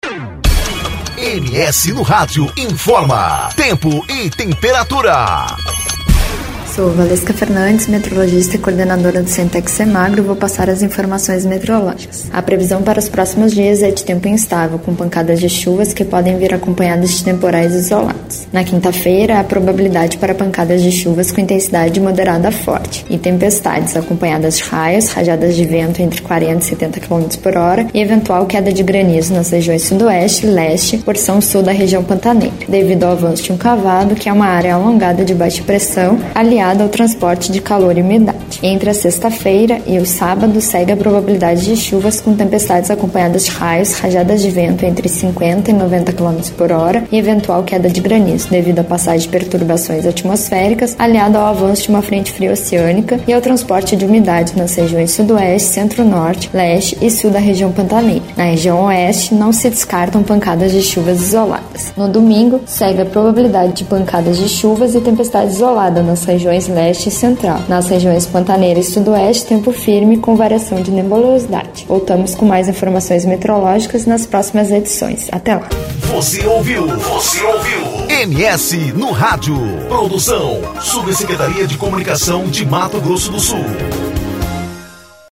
Previsão do Tempo: Final de semana de tempo instável, com chuvas e ventos fortes, e até granizo